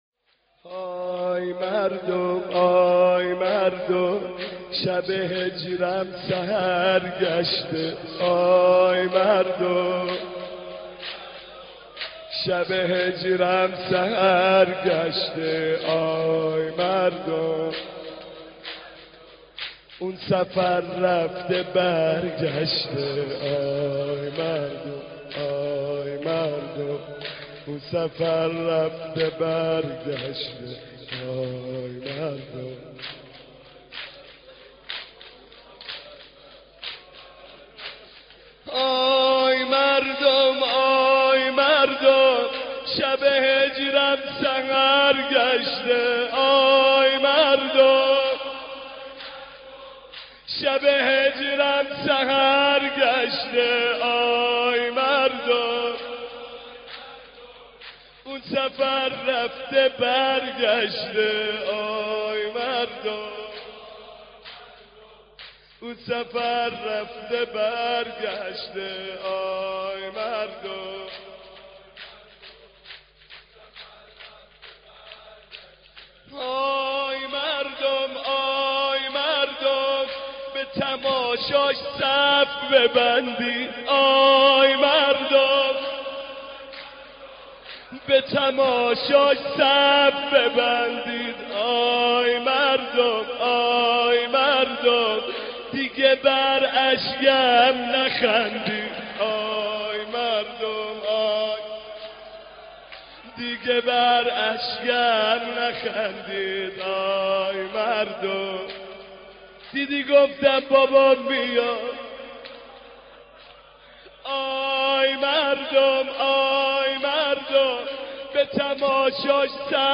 دانلود مداحی آی مردم آی مردم شب هجرم سحر گشته - دانلود ریمیکس و آهنگ جدید
سینه زنی به مناسبت شهادت صدیقه طاهره (س)